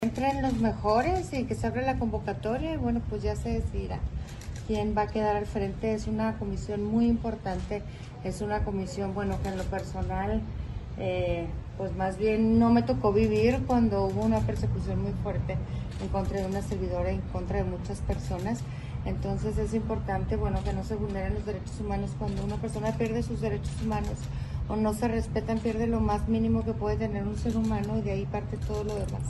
AUDIO: MARÍA EUGENIA CAMPOS, GOBERNADORA DEL ESTADO DE CHIHUAHUA